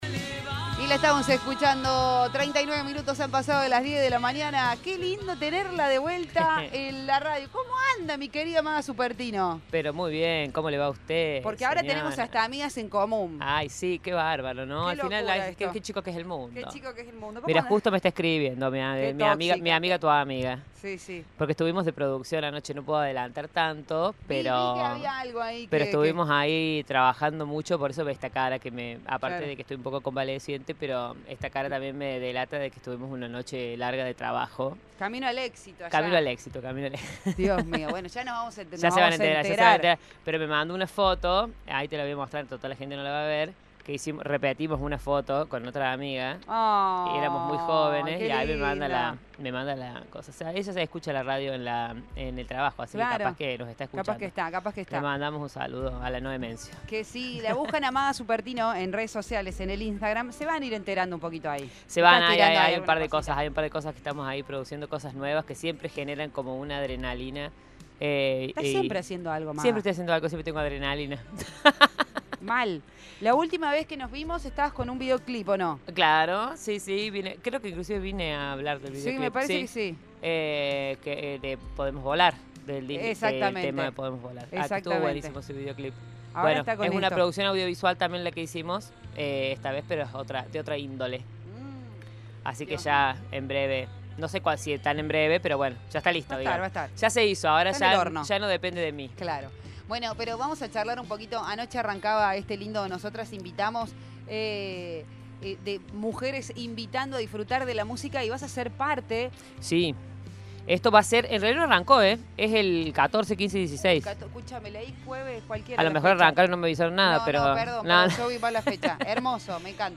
quien recientemente compartió detalles sobre el evento en una entrevista realizada en una emisora de radio local.